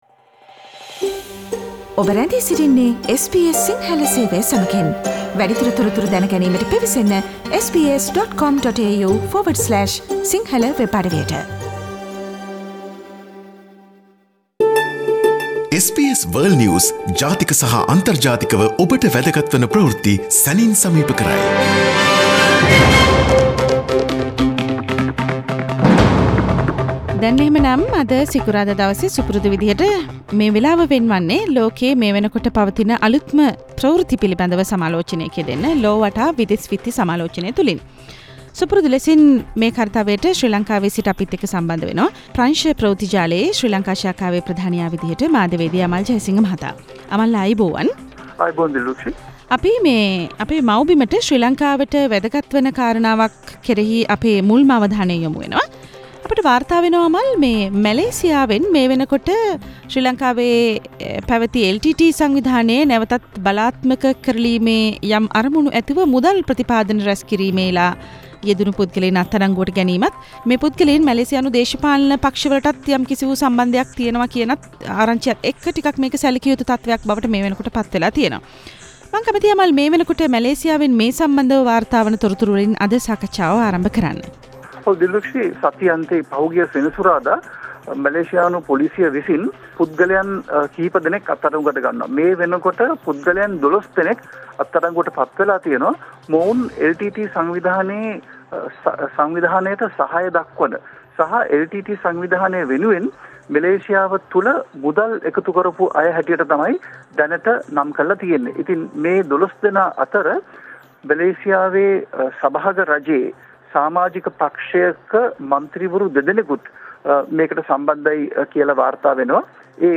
SBS Sinhala weekly world news wrap Source: SBS Sinhala Radio